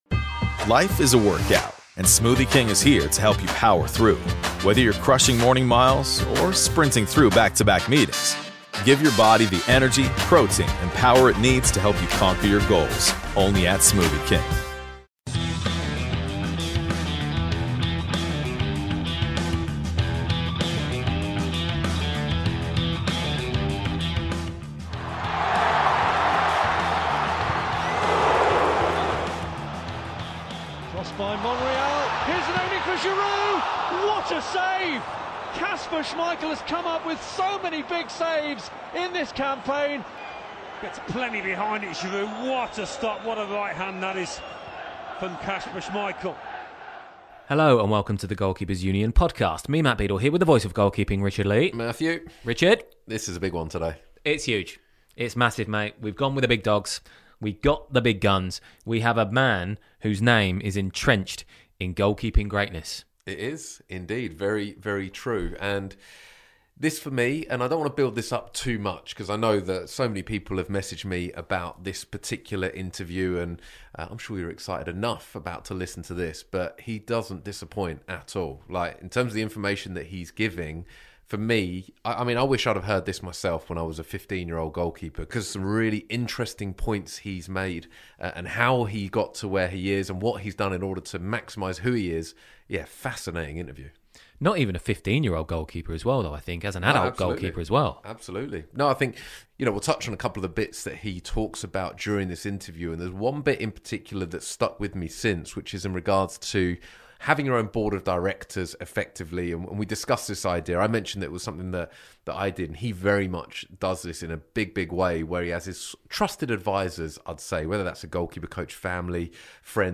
There's certainly a time for stories and career anecdotes, but with the an ever-increasing list of podcasts doing just that, GKU wanted to sit down with Kasper Schmeichel and simply talk goalkeeping.